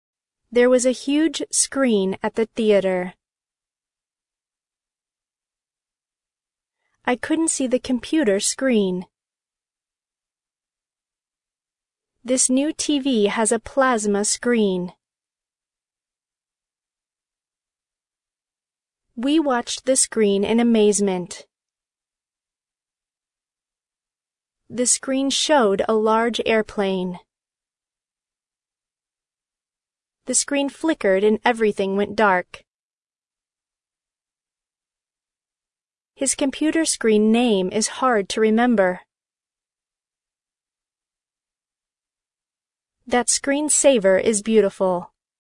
screen-pause.mp3